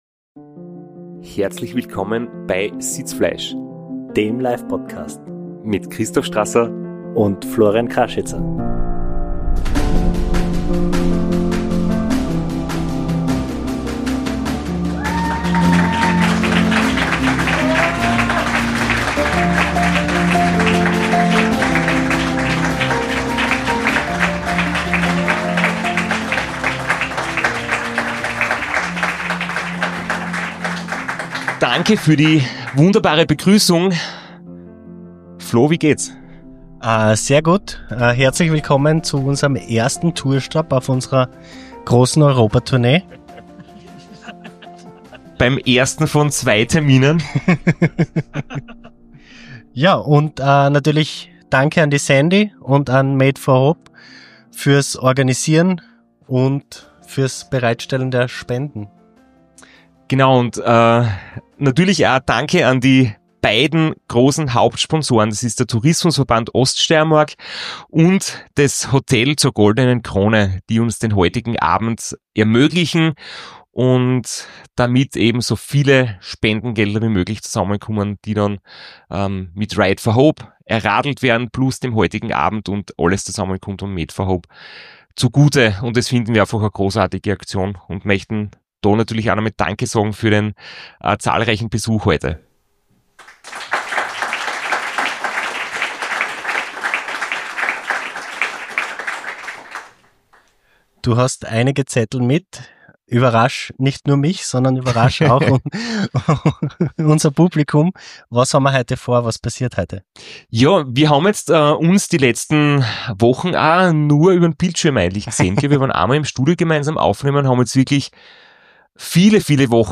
das RACA mit Leihrad und Erfrischungsbad (LIVE)